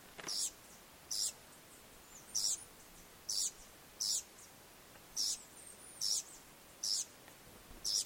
Ratona Ceja Blanca (Troglodytes solstitialis)
Nombre en inglés: Mountain Wren
Fase de la vida: Adulto
Localidad o área protegida: Parque Nacional Calilegua
Condición: Silvestre
Certeza: Fotografiada, Vocalización Grabada
Ratona-Ceja-Blanca-2.mp3